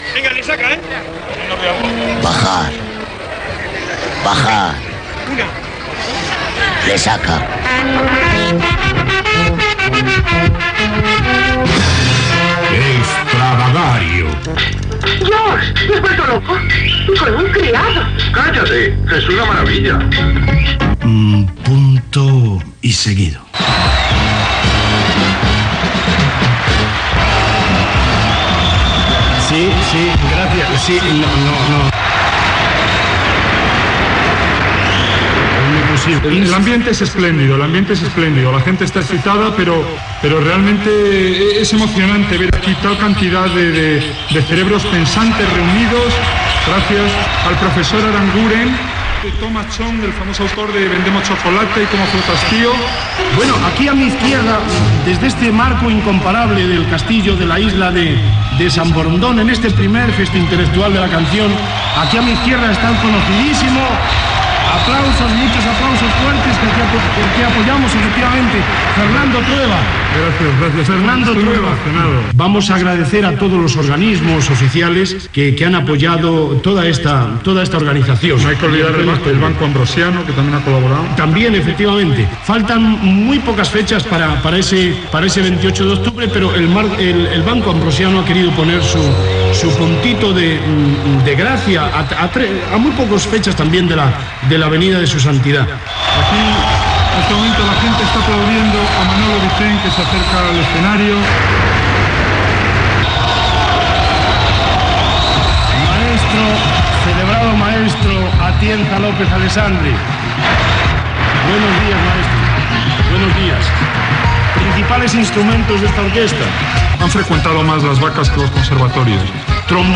Secció "Estravagario" des del primer Festi Intelectual de la Canción amb la intervenció del director Fernando Trueba
Entreteniment